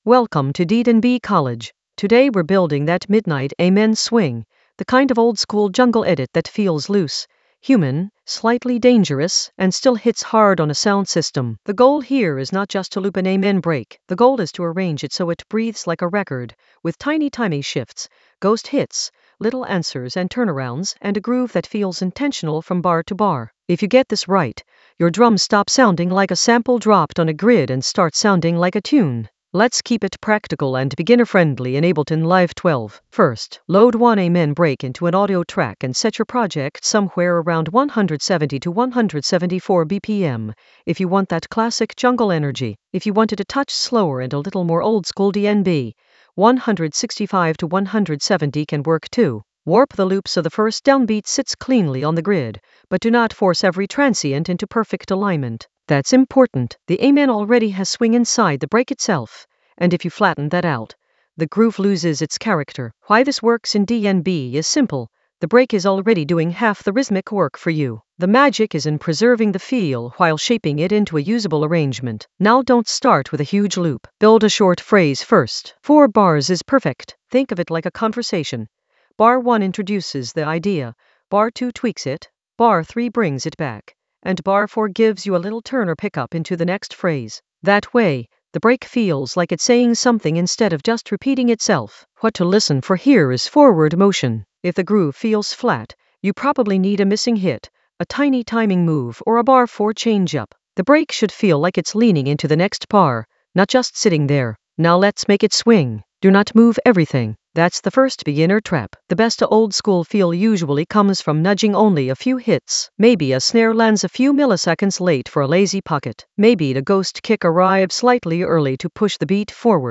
An AI-generated beginner Ableton lesson focused on Midnight Amen oldskool DnB swing: arrange and arrange in Ableton Live 12 for jungle oldskool DnB vibes in the Edits area of drum and bass production.
Narrated lesson audio
The voice track includes the tutorial plus extra teacher commentary.